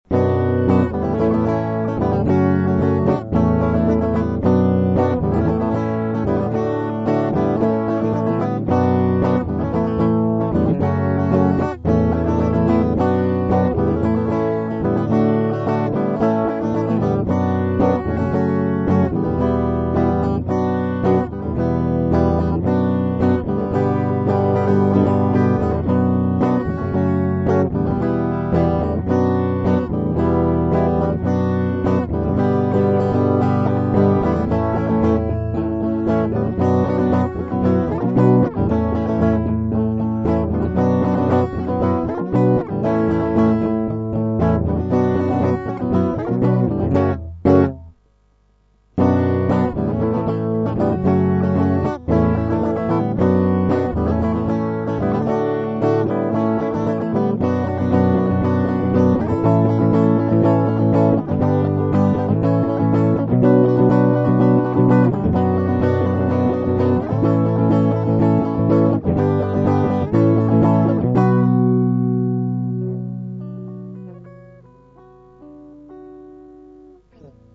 Проигрыш (Hm-A-F#m-A-Hm-A-D-A):
mp3 - а также куплет, припев и окончание